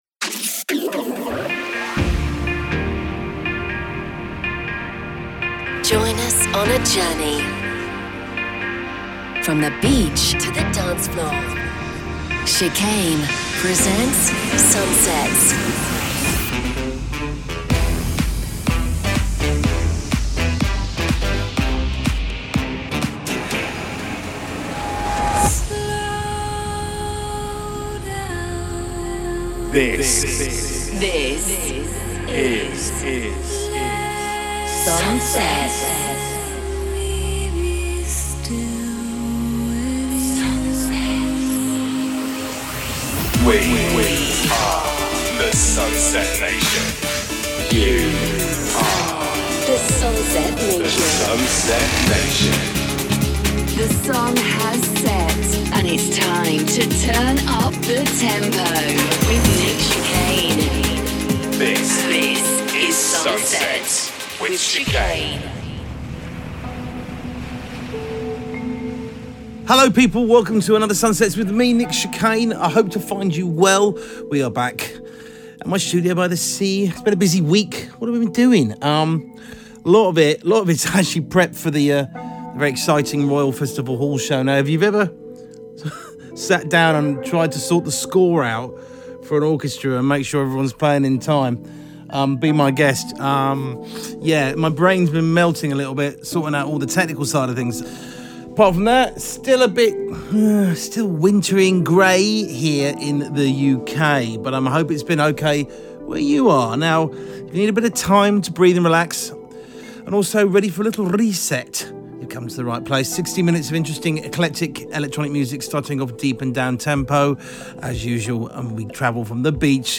Techno DJ Mix